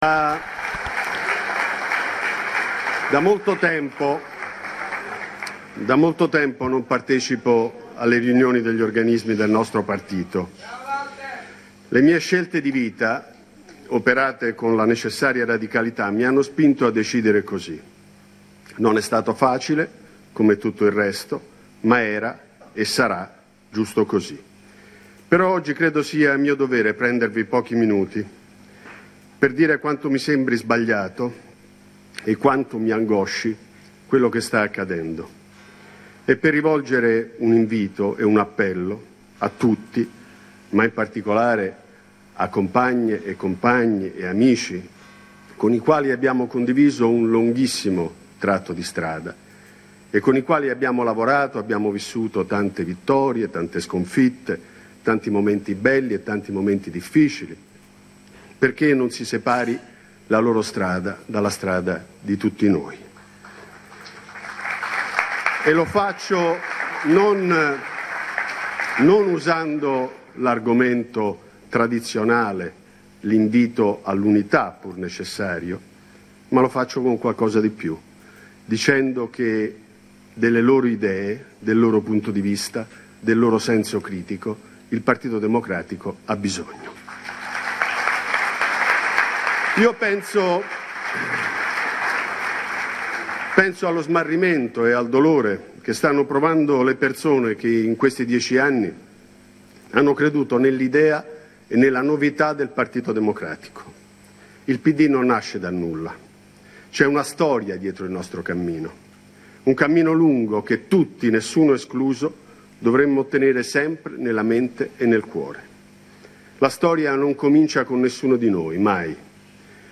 L’intervento di WALTER VELTRONI all’Assemblea nazionale del Partito Democratico, 19 febbraio 2017, AUDIO di 13 minuti